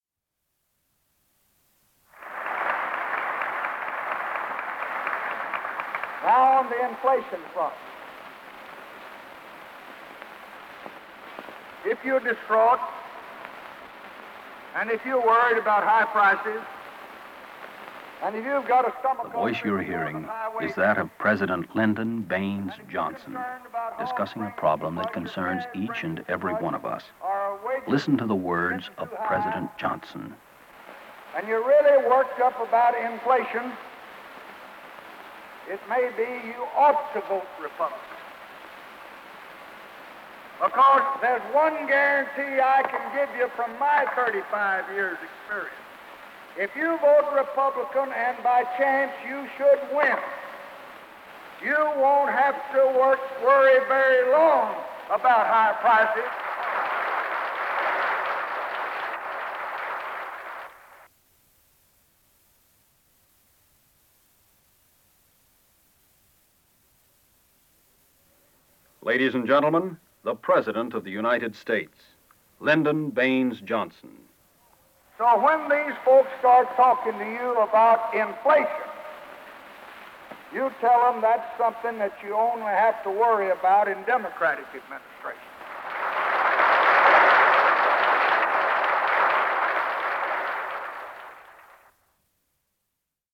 Part of Radio Commercials Targeting President Lyndon Johnson